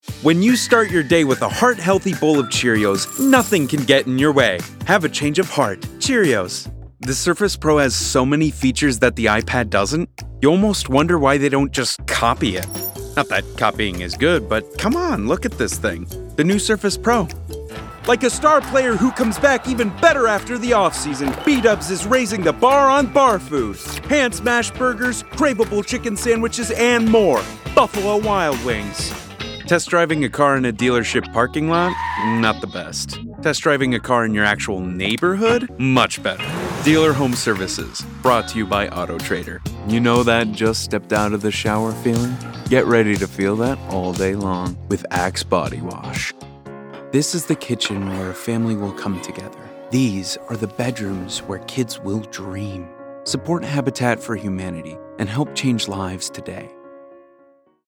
Commercial Demo
American, Scottish, Russian, German, English, French